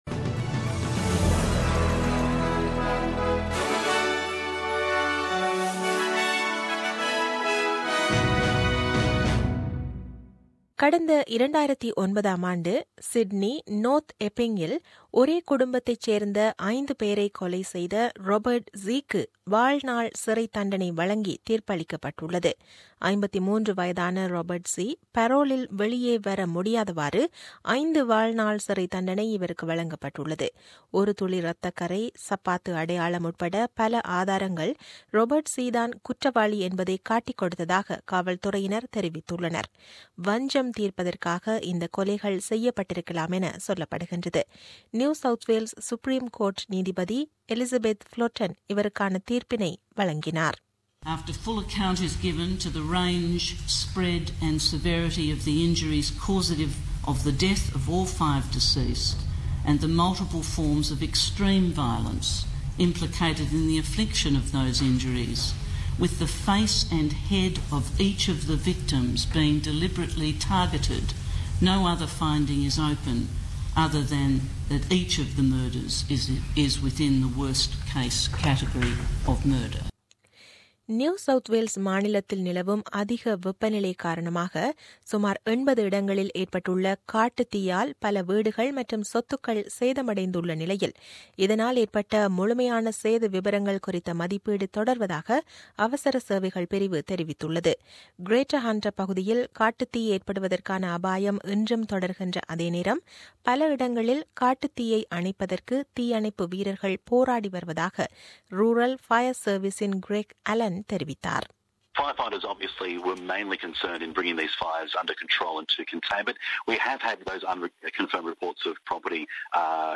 The news bulletin aired on 13 Feb 2017 at 8pm.